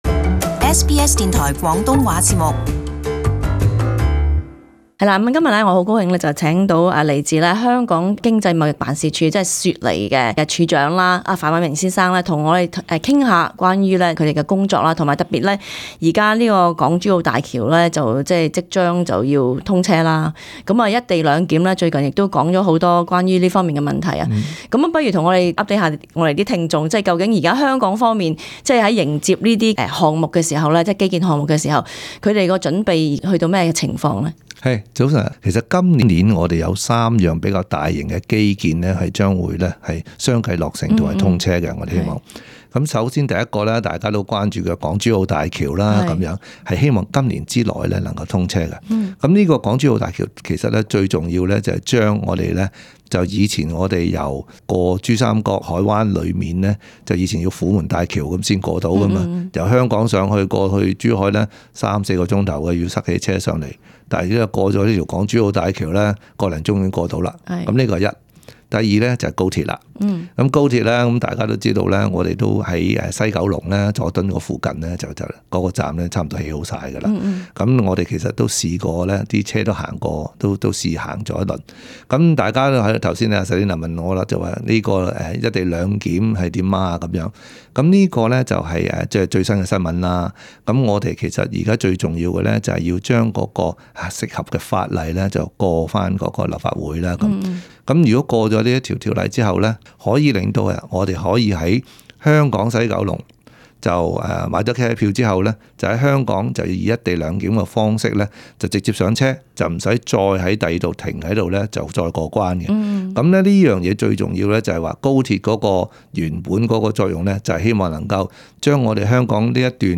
【社區專訪】粵港澳大灣區香港連接工程